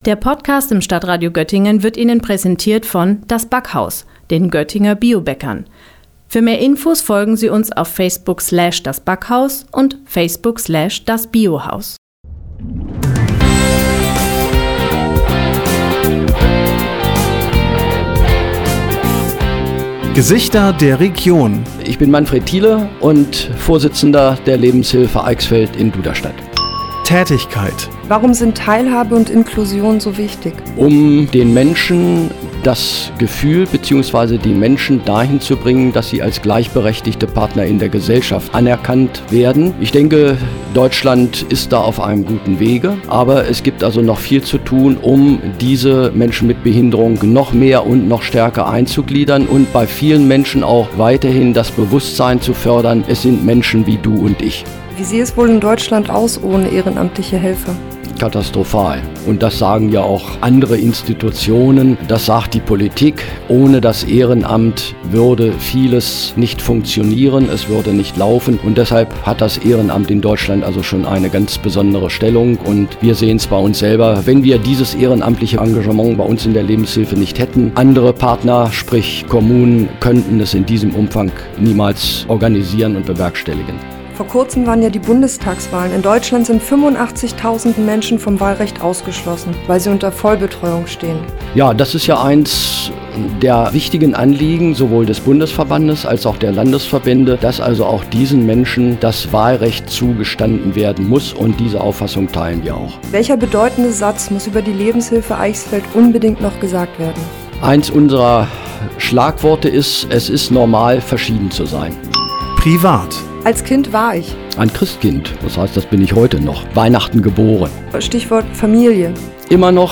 Musikbett